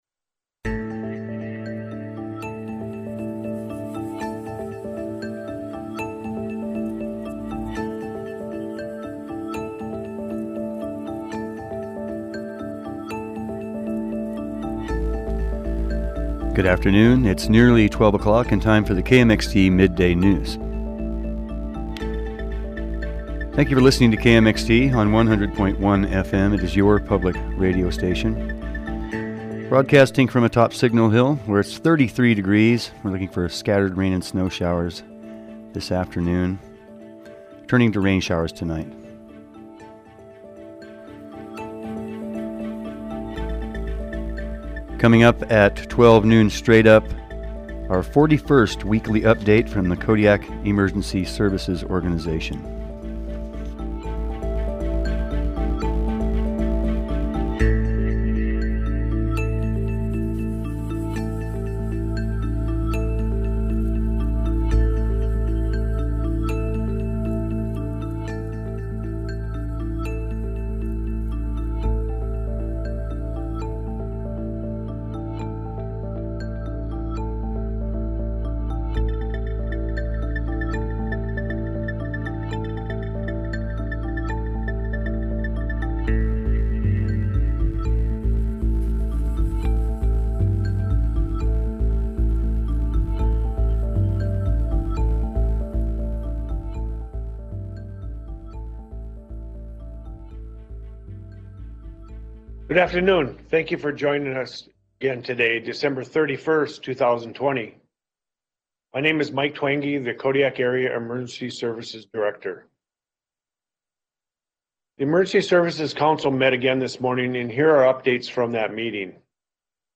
Today on the midday news report: The 41st weekly EOC update Vaccinations in Kodiak are underway. Details on a low abundance of razor clams.